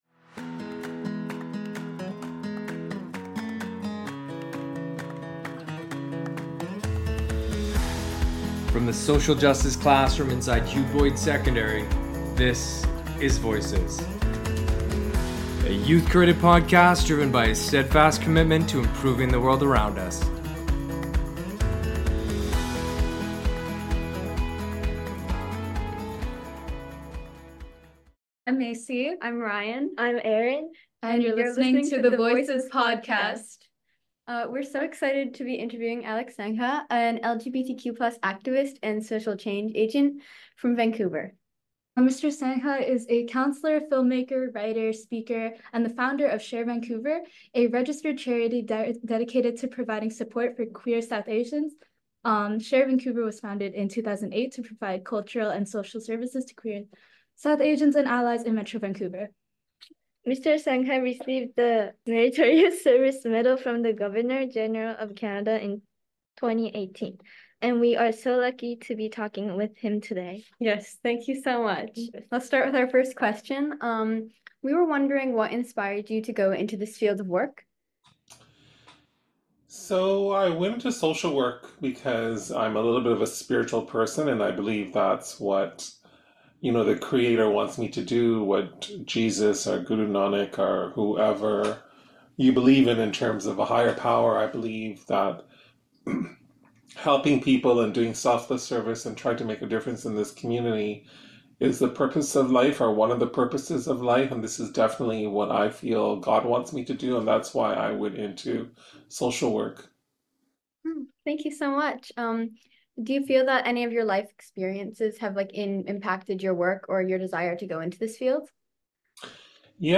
A Canadian youth-created podcast dedicated to addressing issues of fairness, welfare, and justice. We feature interviews with local and global leaders and experts who are committed to improving the world around them.